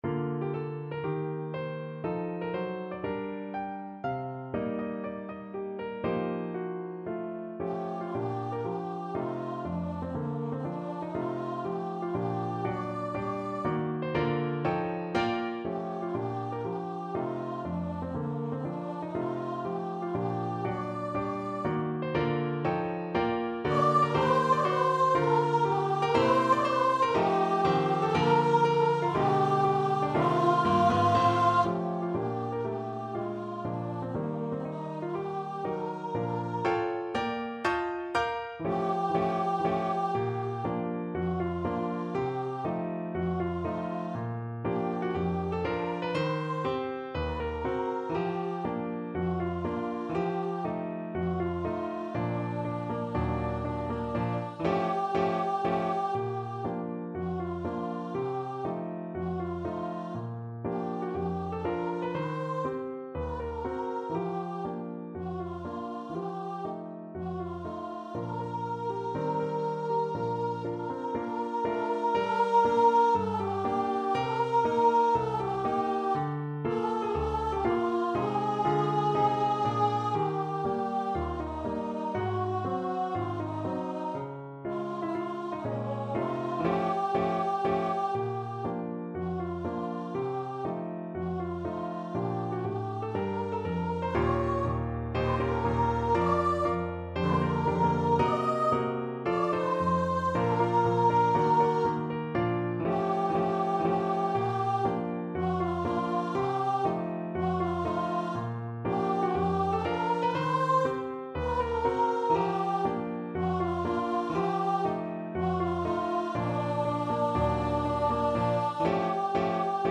4/4 (View more 4/4 Music)
Bb4-Eb6
~ = 120 Moderato